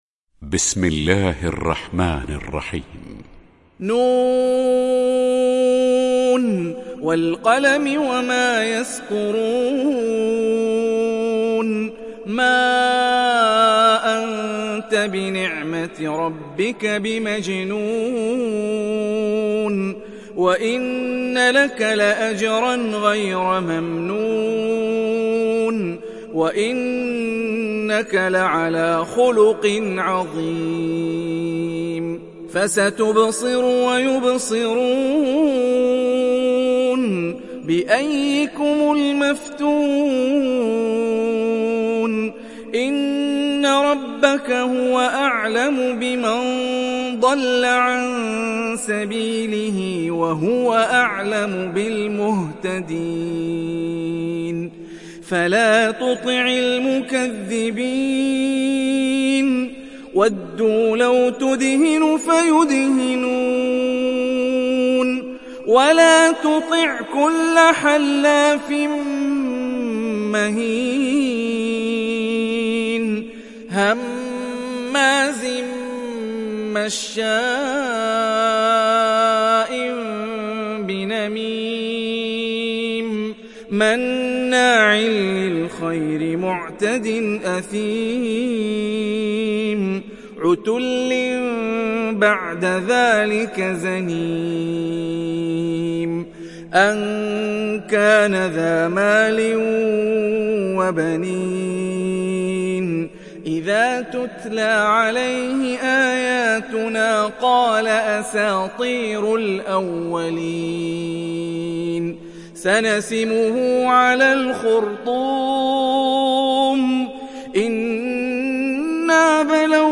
Surat Al Qalam Download mp3 Hani Rifai Riwayat Hafs dari Asim, Download Quran dan mendengarkan mp3 tautan langsung penuh